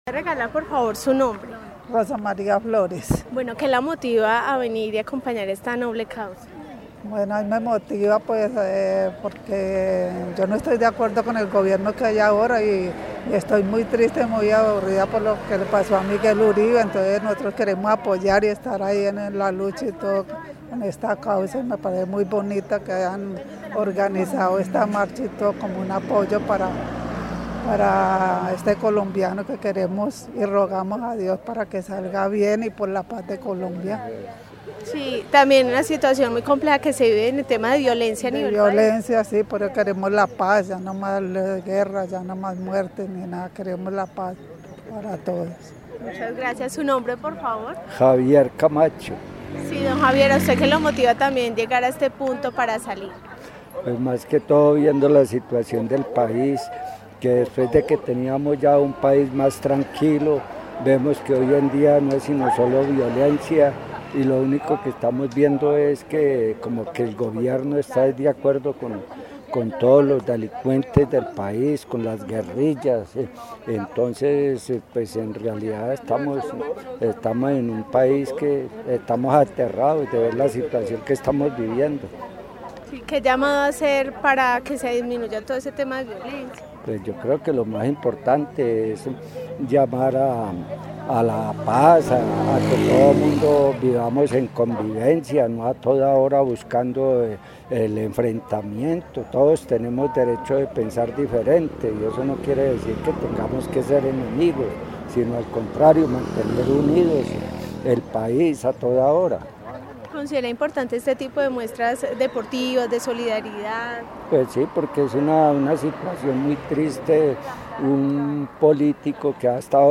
Informe Carrera Por la Vida